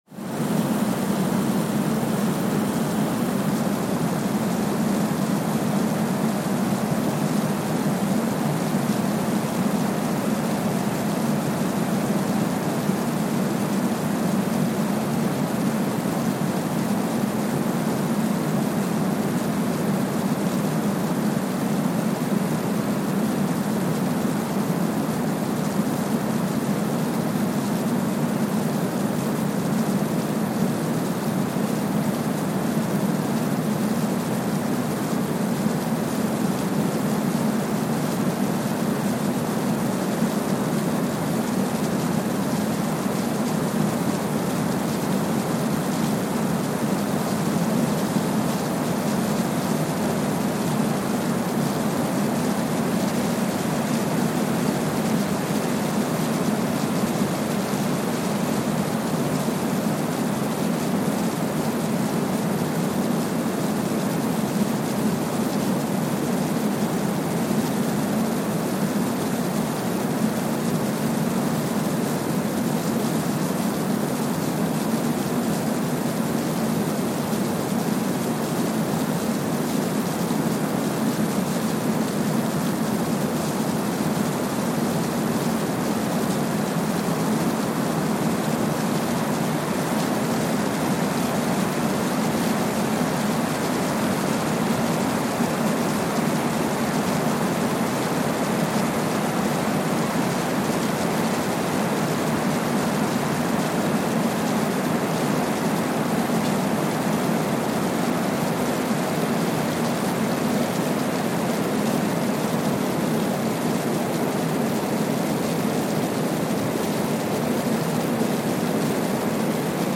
Kwajalein Atoll, Marshall Islands (seismic) archived on October 29, 2020
Sensor : Streckeisen STS-5A Seismometer
Speedup : ×1,000 (transposed up about 10 octaves)
Loop duration (audio) : 05:45 (stereo)